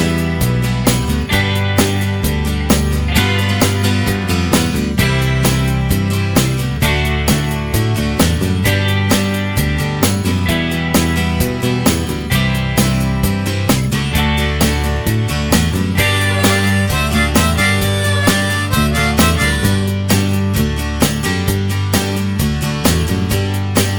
No Harmonica Pop (1960s) 2:53 Buy £1.50